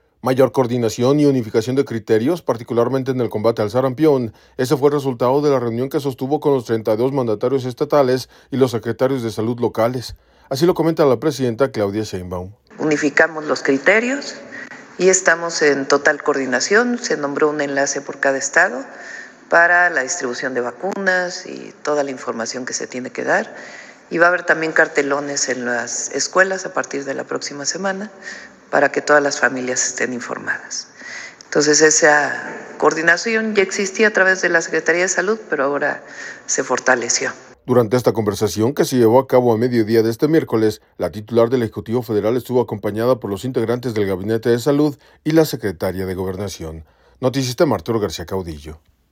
Mayor coordinación y unificación de criterios, particularmente en el combate al sarampión, ese fue el resultado de la reunión que sostuvo con los 32 mandatarios estatales y los secretarios de Salud locales, así lo comenta la presidenta Claudia Sheinbaum.